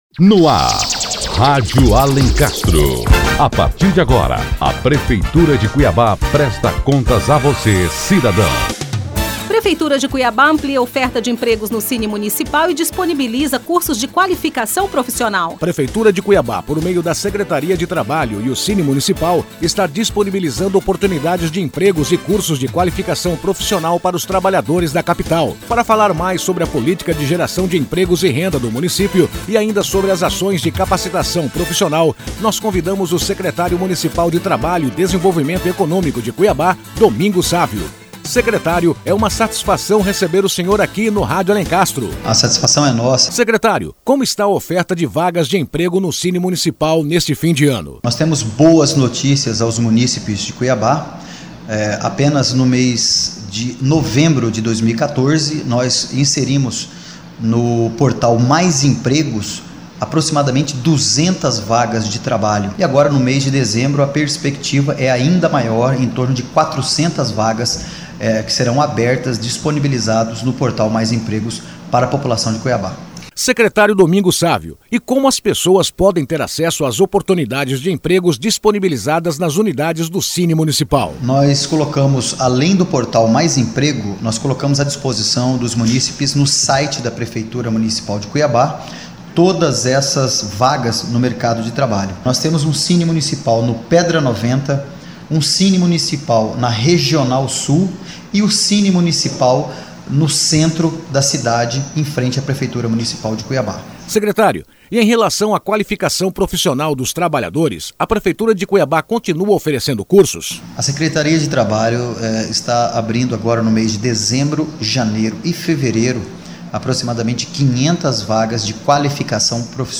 Confira agora a entrevista com o Secretário Municipal de Trabalho e Desenvolvimento Econômico, Domingos Sávio, e saiba mais sobre as ações de captação e geração de empregos da Prefeitura. p g r pmcba 171.mp3